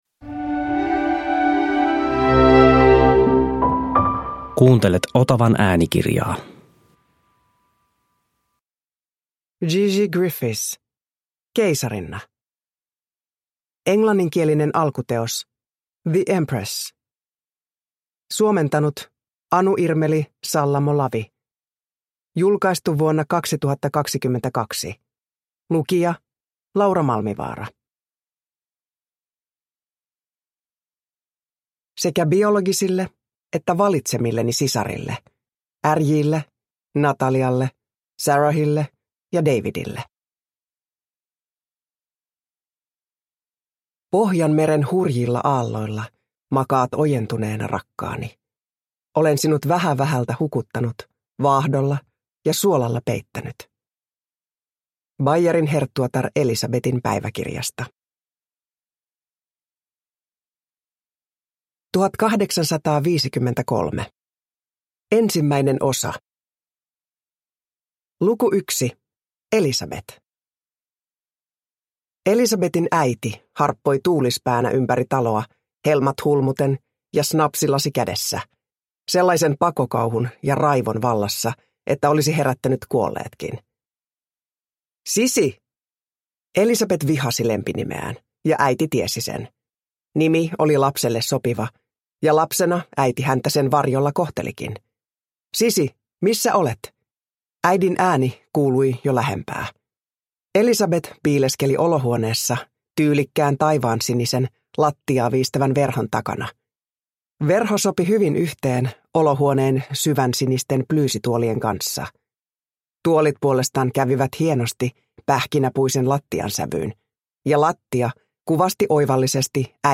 Keisarinna – Ljudbok – Laddas ner
Uppläsare: Laura Malmivaara